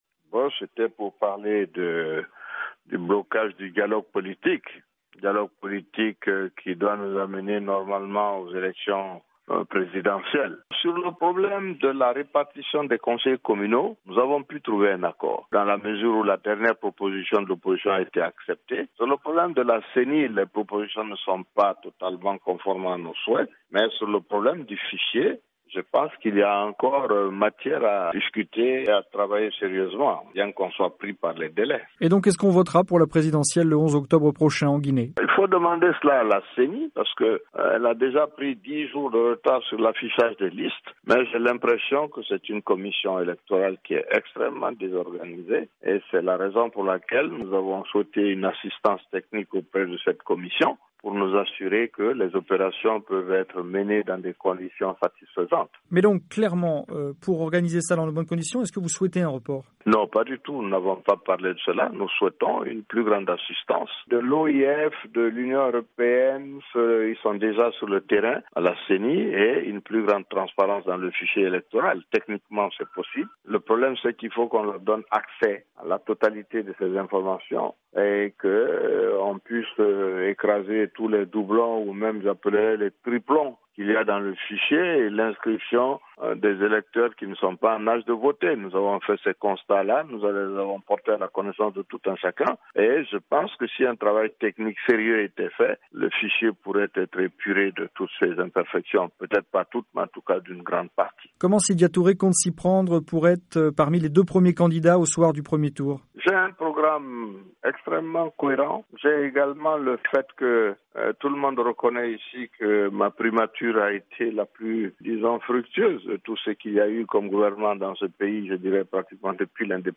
A moins de deux mois de la présidentielle du 11 octobre prochain en Guinée des incertitudes demeurent sur le processus électoral. L’opposition critique toujours le fichier électoral et la Ceni C’est ce qu’a répété au cours d’une entrevue Sydia Toure à Alpha Condé.